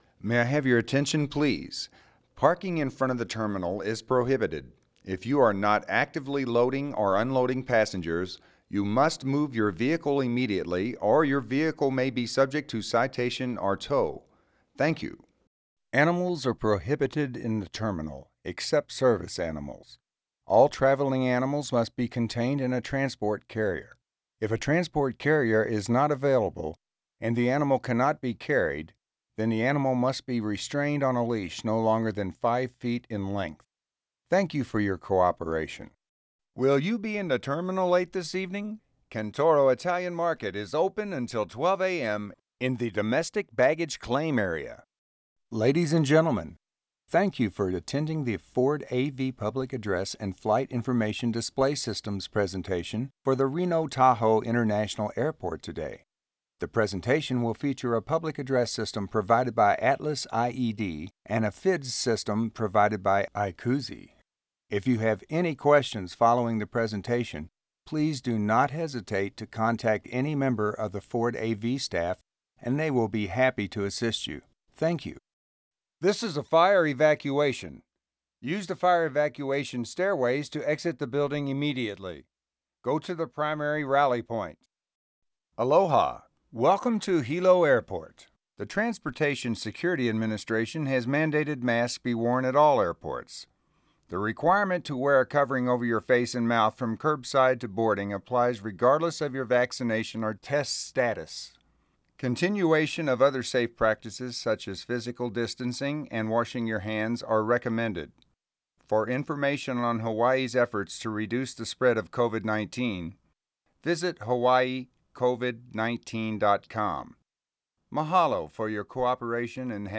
Voice-over-mix.wav